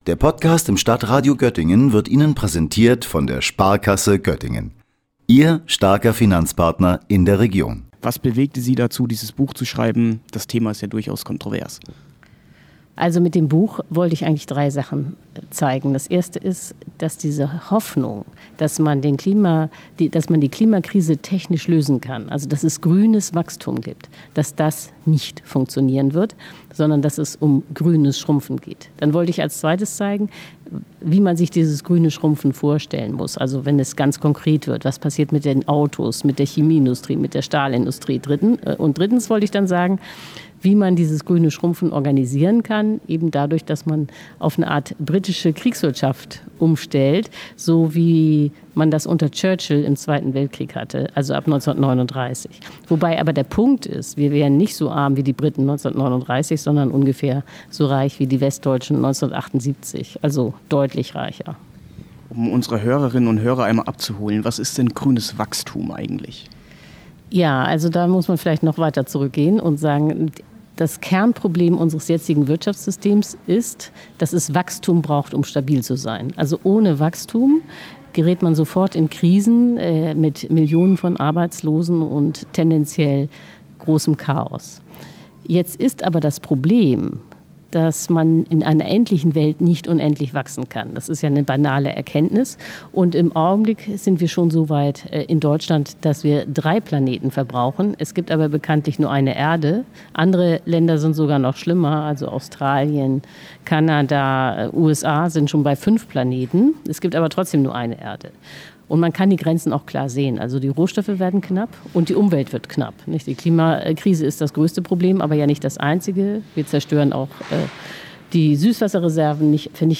Beiträge > Über grünes Wachstum und die Grenzen des Kapitalismus - Journalistin Ulrike Hermann im Interview - StadtRadio Göttingen